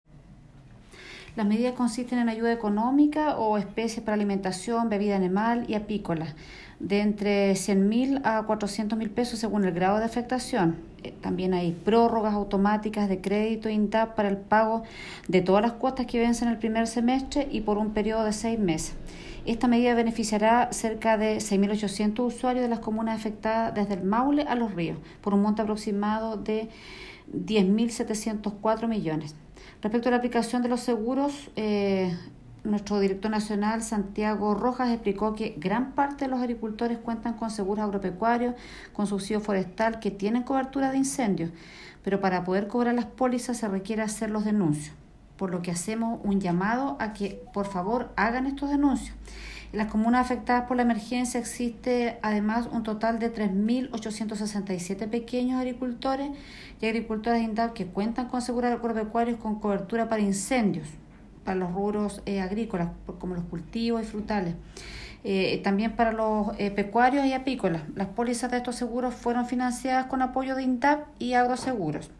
Directora-regional-INDAP-sobre-medidas-para-incendios.m4a